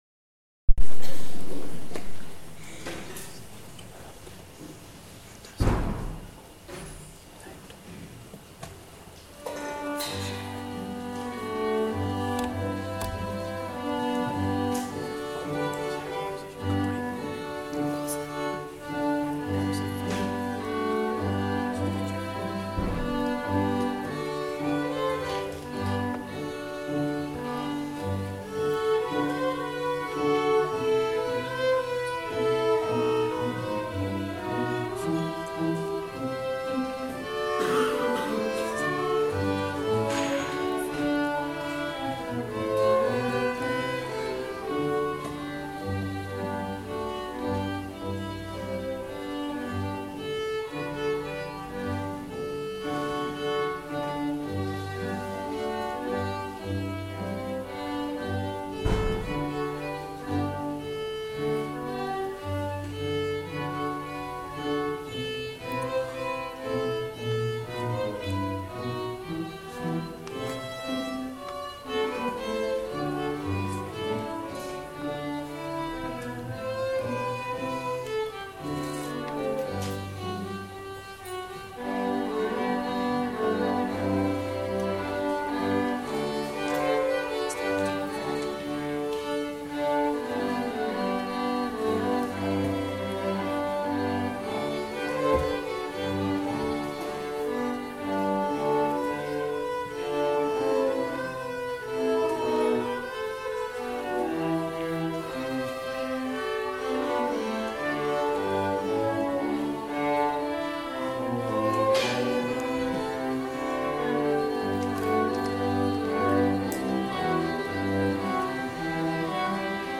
string quintet